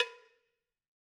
Cowbell1_Hit_v3_rr1_Sum.wav